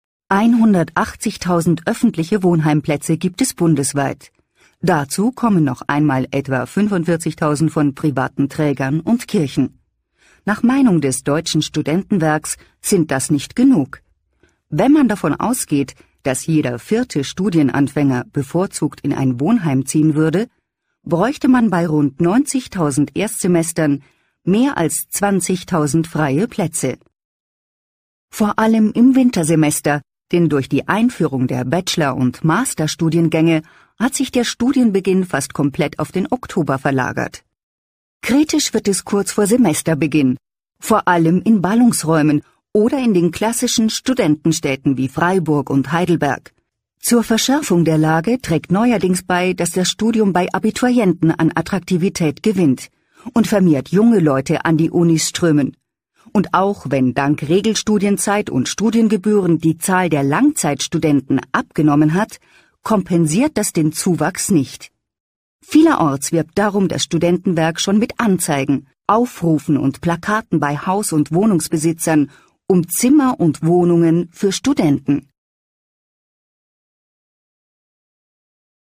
Klicken Sie auf die Wiedergabetaste, und hören Sie sich einen Ausschnitt aus einem Radiobericht über die Wohnsituation der Schüler an.